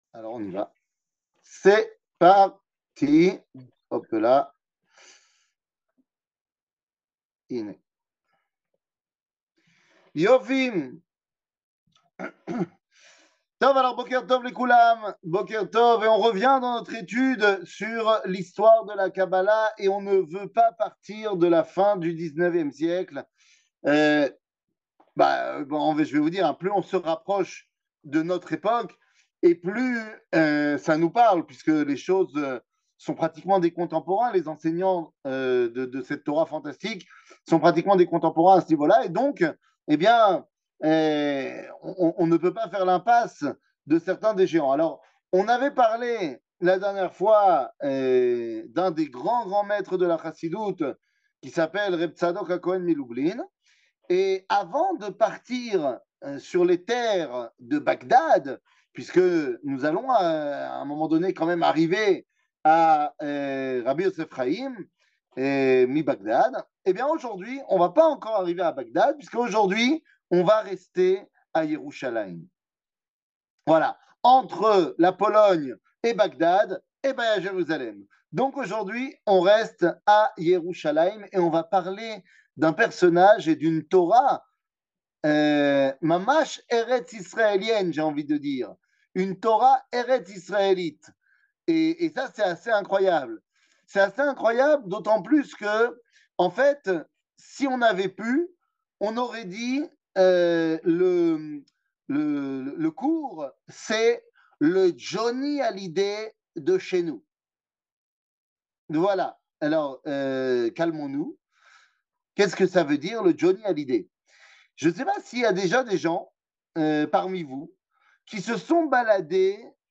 שיעורים, הרצאות, וידאו
שיעור